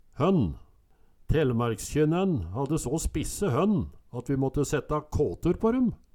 hønn - Numedalsmål (en-US)